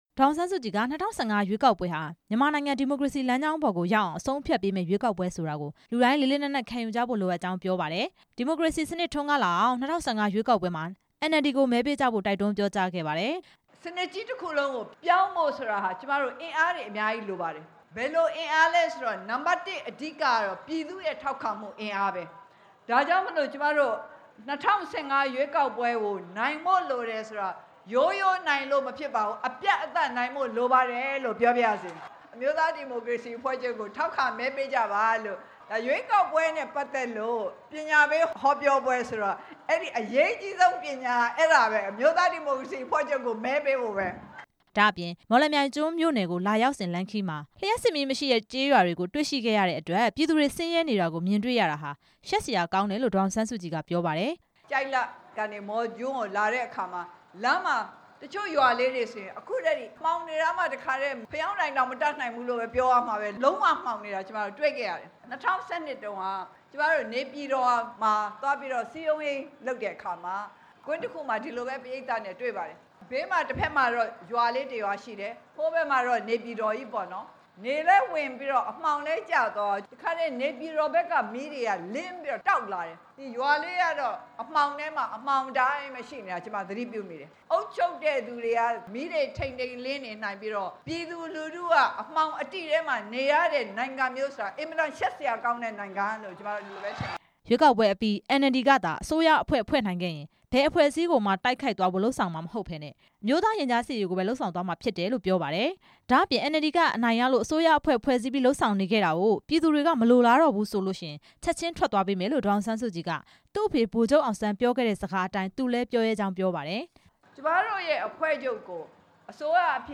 အမျိုးသားဒီမိုကရေစီအဖွဲ့ချုပ် ဥက္ကဌ ဒေါ်အောင်ဆန်းစုကြည်ဟာ ဧရာဝတီတိုင်း မော်လမြိုင်ကျွန်းမြို့ မြို့မဘောလုံး အားကစားကွင်းမှာ ရွေးကောက်ပွဲဆိုင်ရာ ပညာပေးဟောပြောပွဲကို ဒီနေ့မနက်ပိုင်းမှာ ကျင်းပခဲ့ပါတယ်။